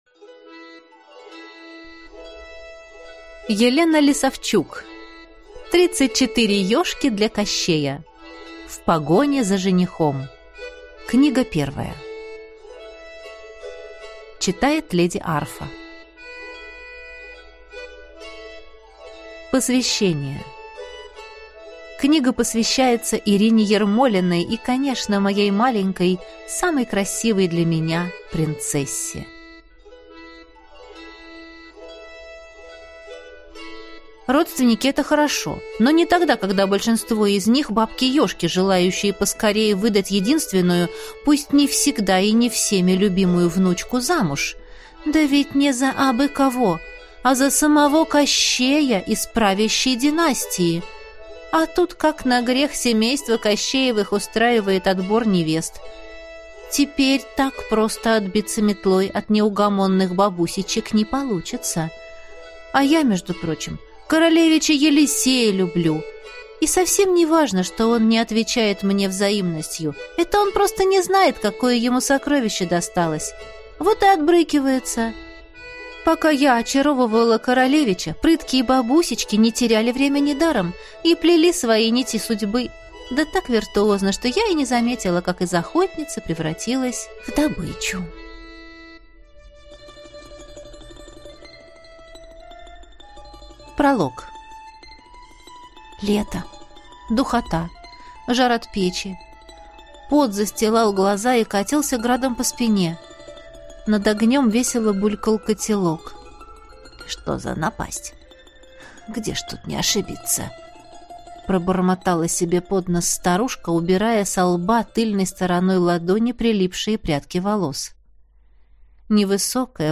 Аудиокнига Тридцать четыре Ежки для Кощея. В погоне за женихом | Библиотека аудиокниг